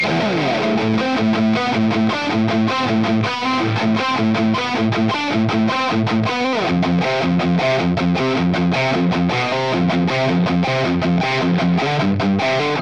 Metal Riff
RAW AUDIO CLIPS ONLY, NO POST-PROCESSING EFFECTS